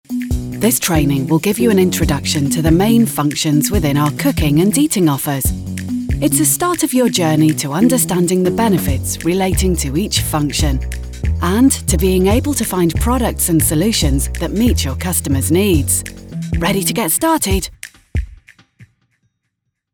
Englisch (Britisch)
Unverwechselbar, Vielseitig, Freundlich
E-learning